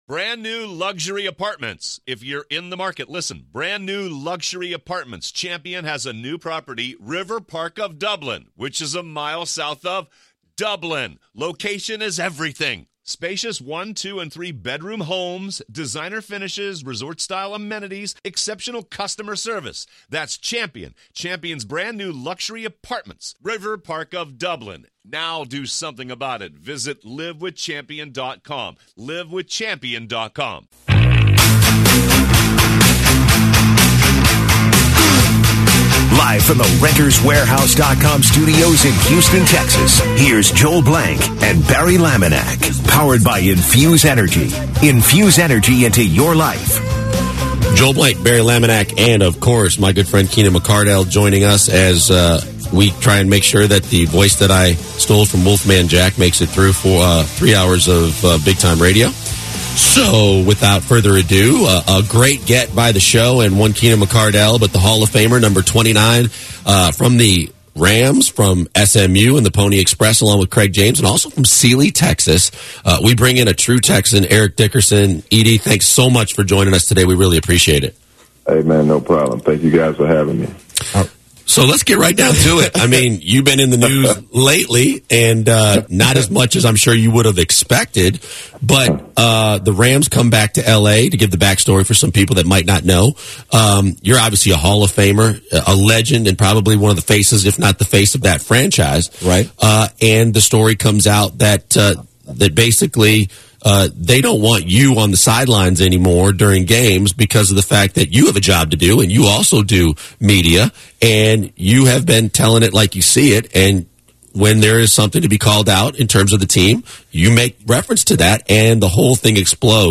Full Eric Dickerson Interview on the Rams & Jeff Fisher